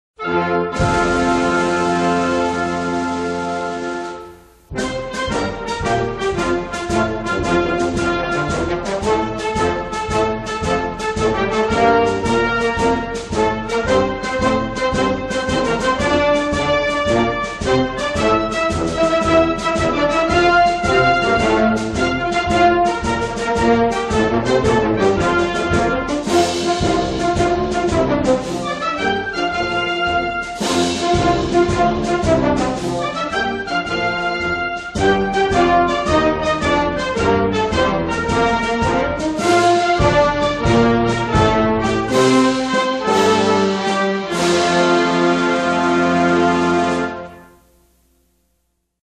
國歌